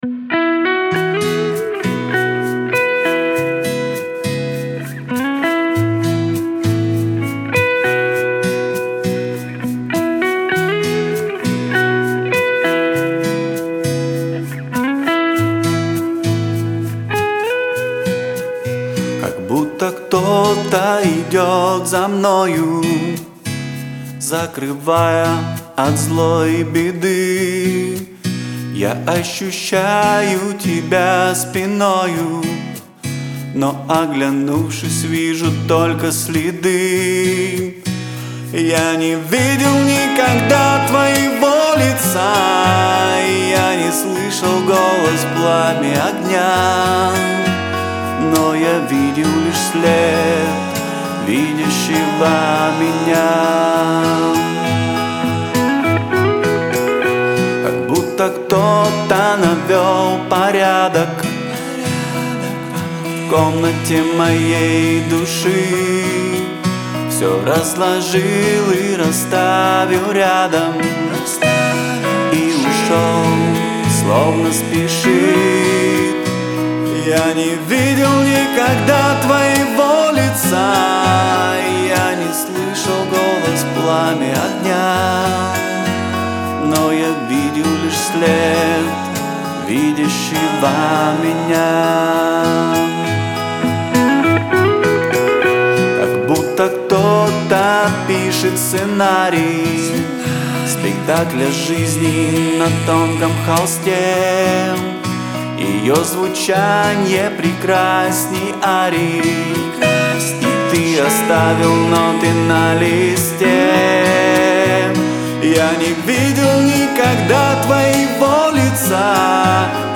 песня
738 просмотров 514 прослушиваний 94 скачивания BPM: 100